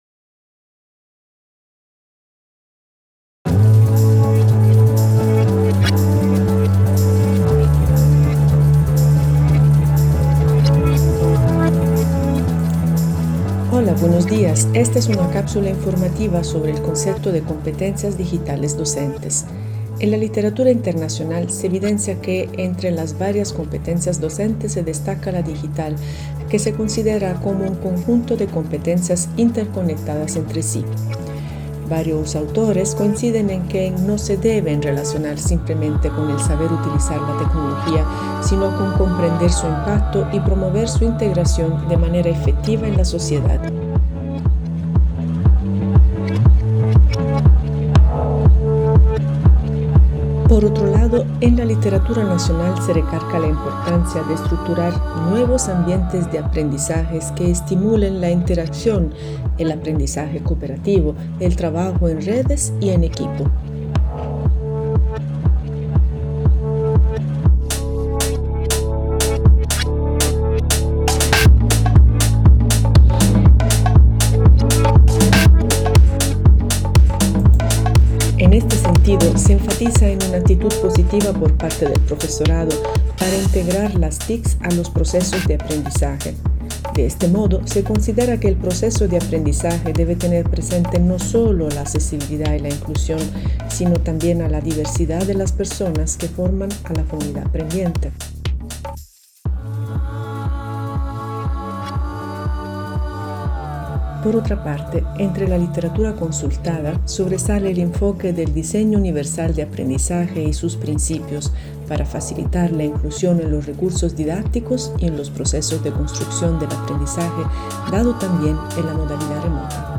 Cápsula informativa oral: competencias digitales docentes
Para este proyecto, por cápsula informativa oral se entiende un breve podcast cuya duración no supera los tres minutos.